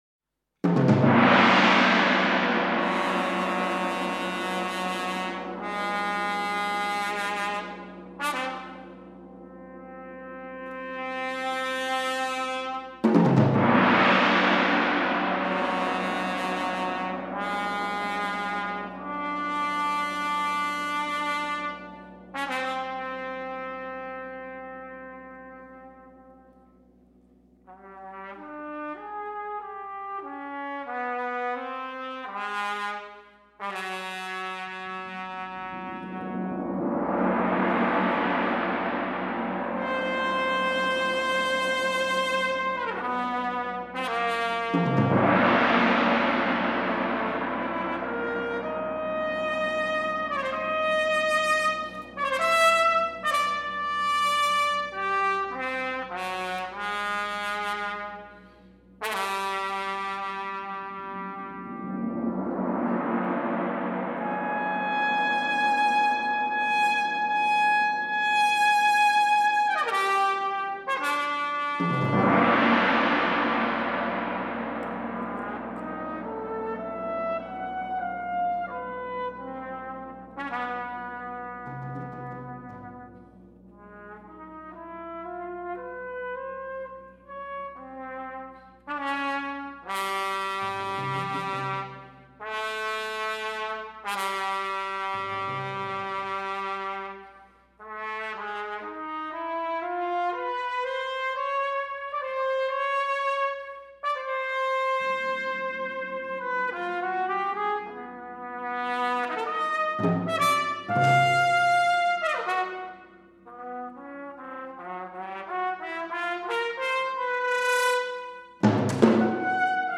World Première Recording.